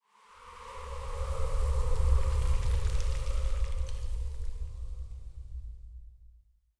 housewind13.ogg